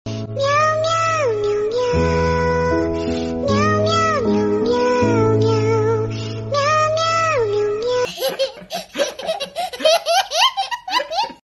🌧 Cute Cats in the sound effects free download
🌧 Cute Cats in the Rain Wearing Adorable Raincoats – Meow Meow Sad Meo meo videos😄👌
💧 Gentle, sad meows as they react to the rainy weather. 🎶 A soothing, emotional soundtrack that perfectly matches the rainy mood.